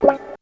137 GTR 12-L.wav